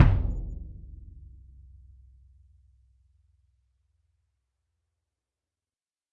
音乐会大鼓 " 交响乐大鼓Vel41
描述：Ludwig 40''x 18''悬挂式音乐会低音鼓，通过多种速度的头顶麦克风录制。
标签： 低音 音乐会 交响乐 管弦乐
声道立体声